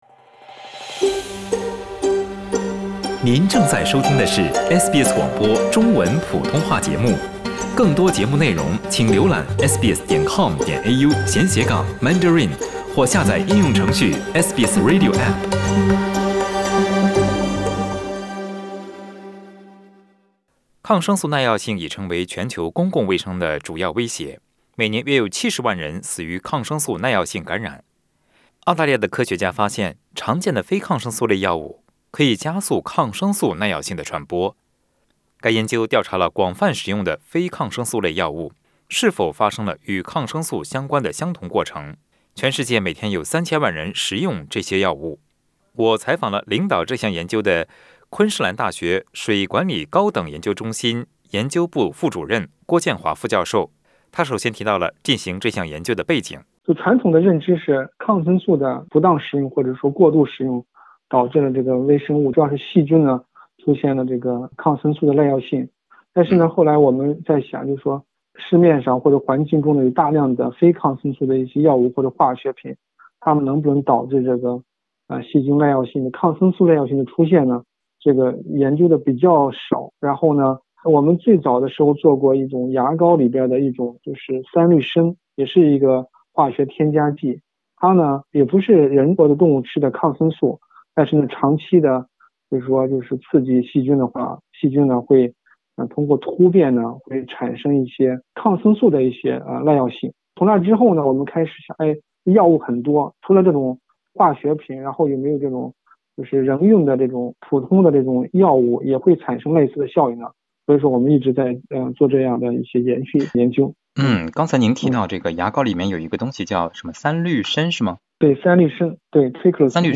請您點擊文首圖片收聽詳細的寀訪內容。